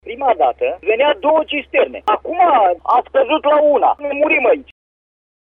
voce-om-Doman-HAR.mp3